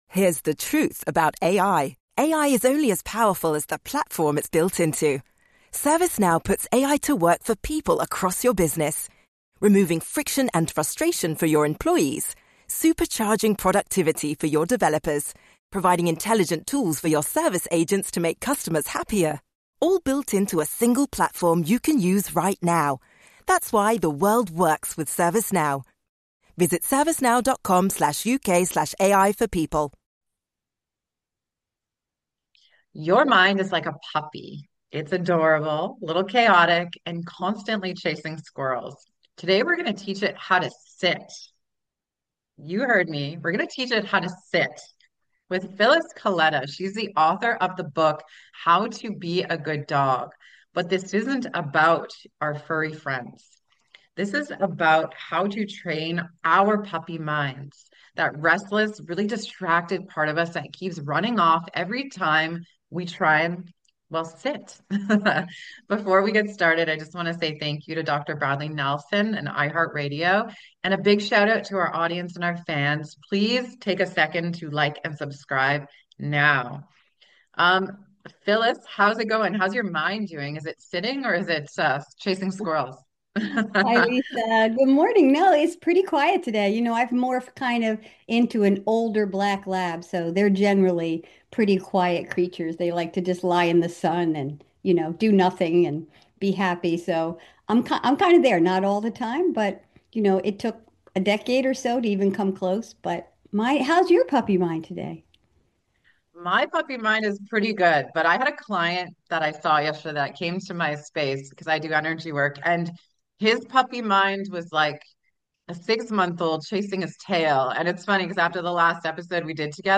for a heartfelt conversation.